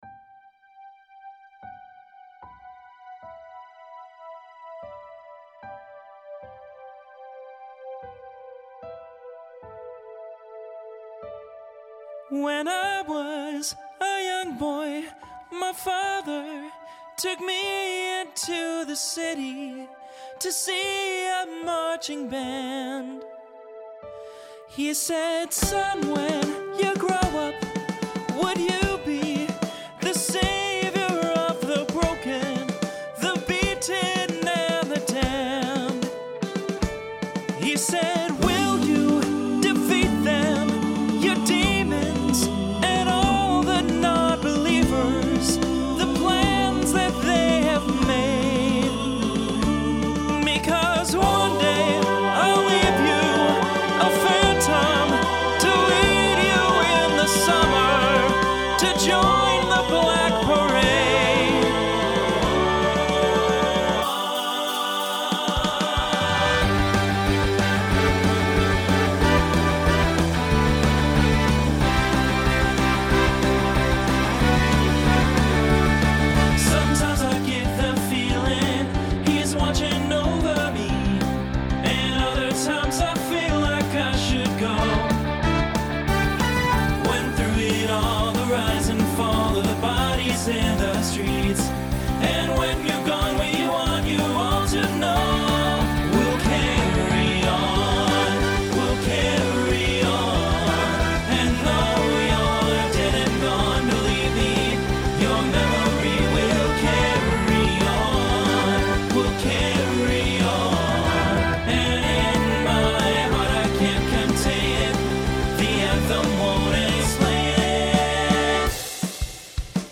Genre Rock
Transition Voicing Mixed